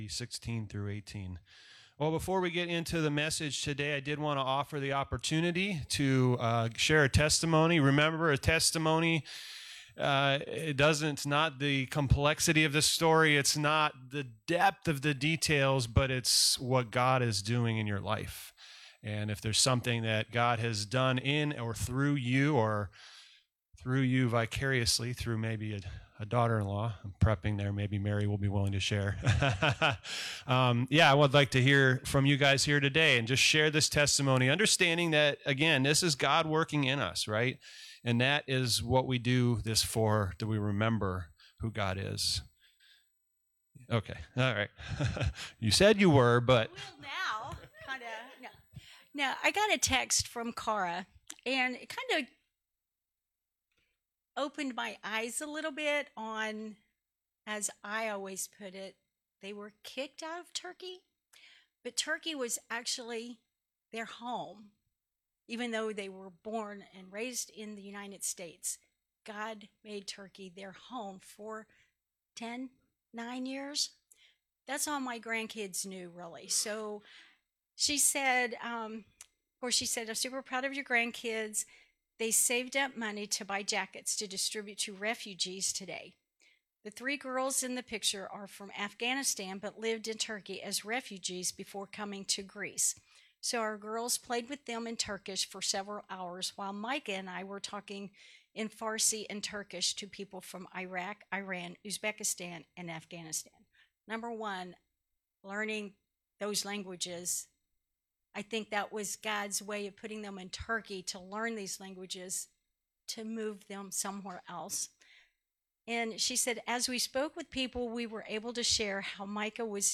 Sunday, November 24th, 2024 (Testimonies)
This is a recording of the testimonies given during the Testimony time on the 11/24/24. Each month we do this on the 4th Sunday.